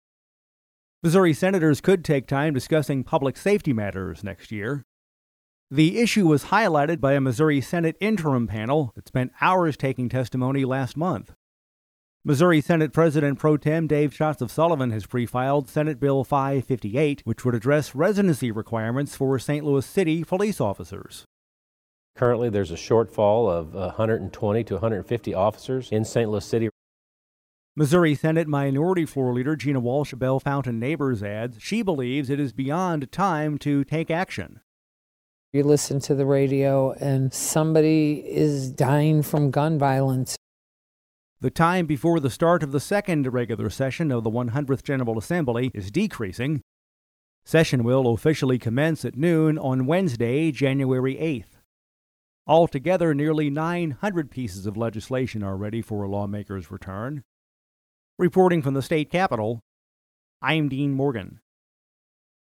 Dec. 24: Missouri senators may spend time discussing public safety next session. Among the pre-filed measures on this subject is Senate Bill 558, which would address residency requirements for St. Louis City police officers. We’ve included actualities from Missouri Senate President Pro Tem Dave Schatz, R-Sullivan, and Missouri Senate Minority Floor Leader Gina Walsh, D-Bellefontaine Neighbors
feature report